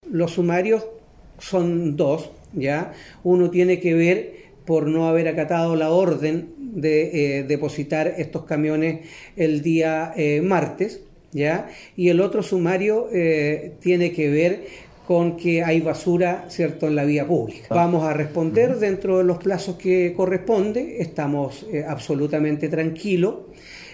El alcalde de Ancud señaló que pese los sumarios establecidos por la autoridad de salud buscan establecer eventuales responsabilidades, la entidad espera responder a estos requerimientos en los plazos establecidos.